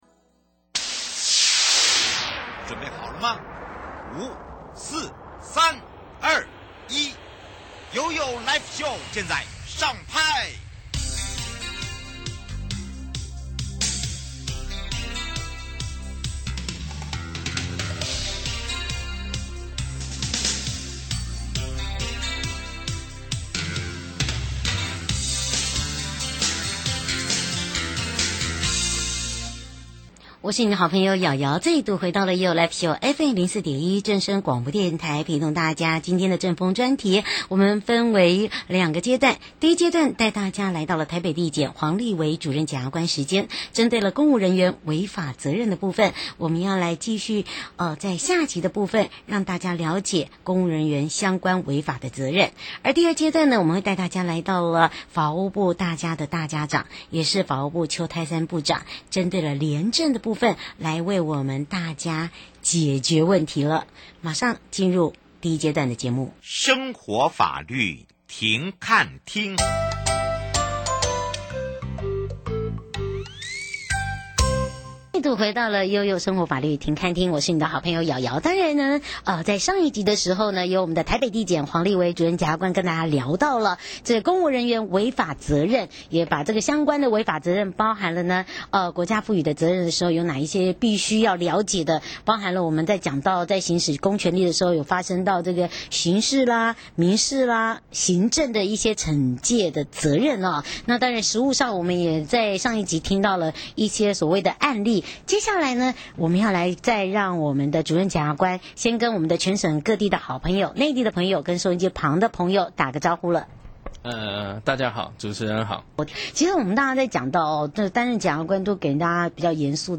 受訪者： 1. 法務部邱太三部長 2. 台北地檢黃立德主任檢察官 節目內容： 1.